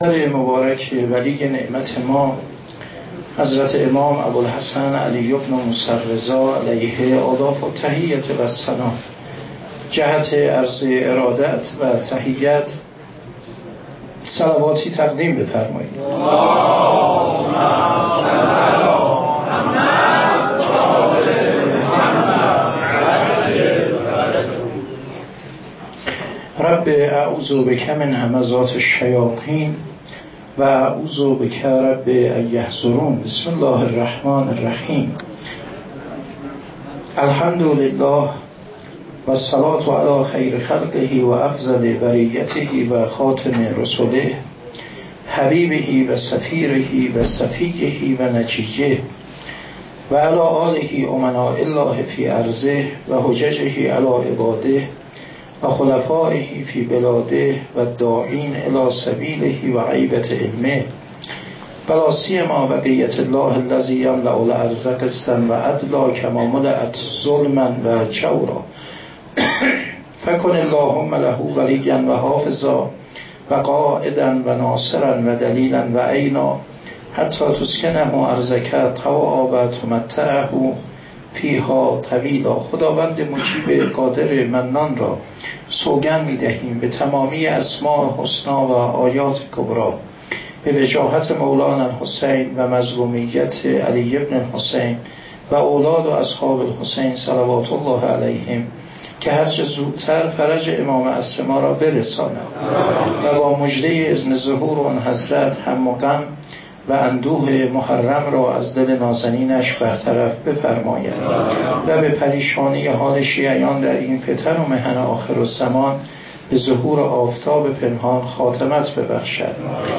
هیجدهم محرم 96 - هیئت آبنا الرضا - سخنرانی